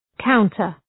Προφορά
{‘kaʋntər}